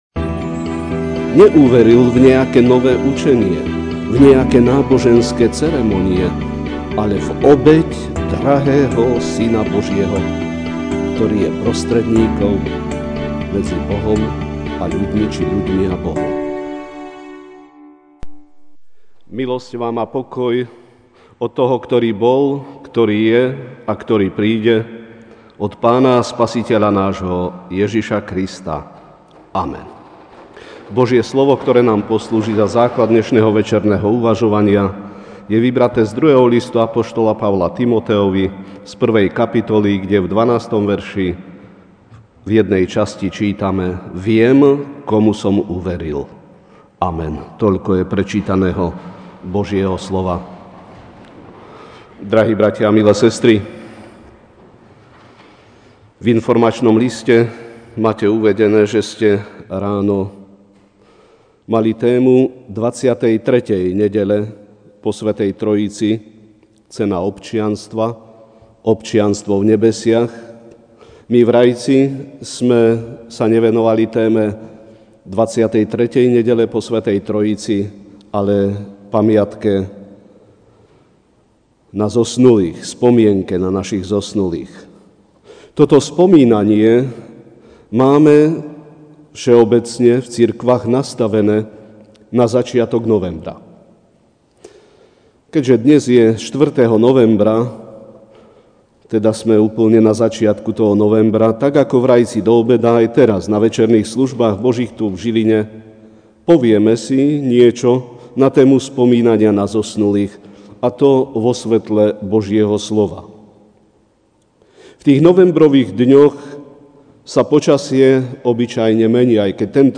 Večerná kázeň: Pamiatka na zosnulých (2Tim 1, 12)